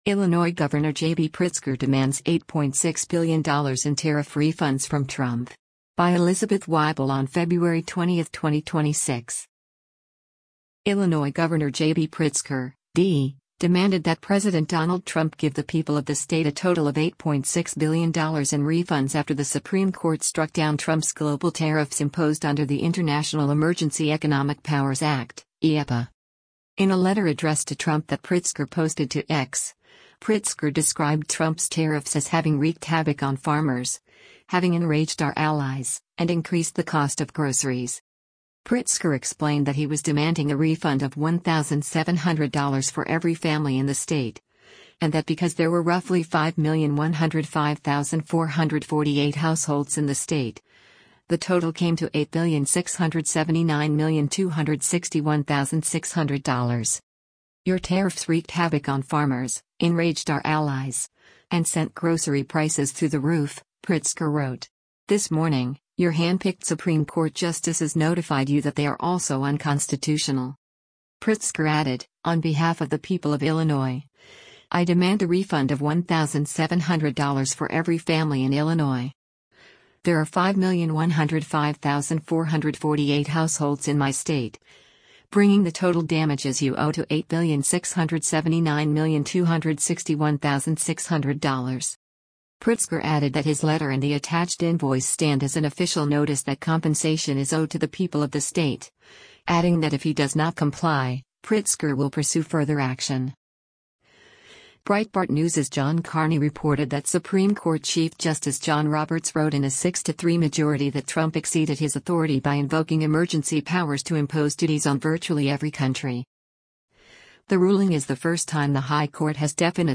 Illinois Governor JB Pritzker speaks to the press following a visit to the National Museum